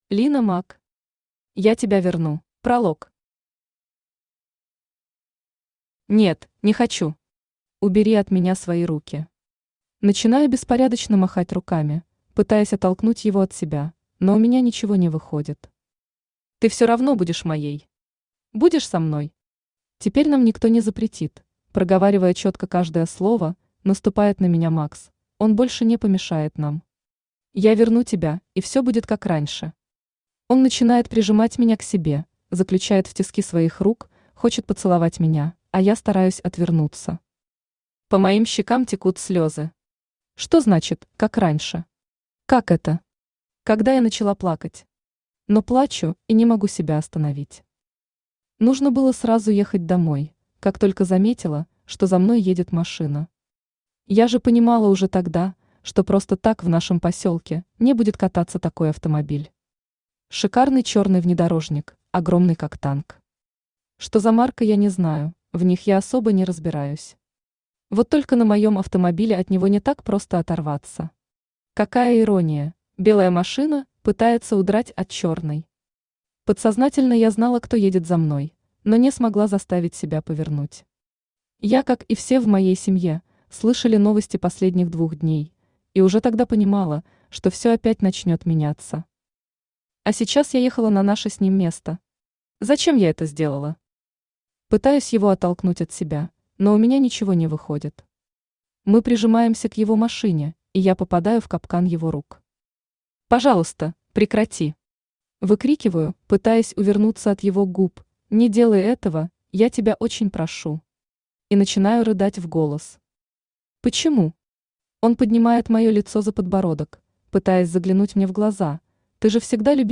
Аудиокнига Я тебя верну | Библиотека аудиокниг
Aудиокнига Я тебя верну Автор Лина Мак Читает аудиокнигу Авточтец ЛитРес.